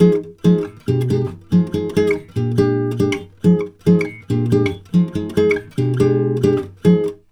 140GTR FM7 3.wav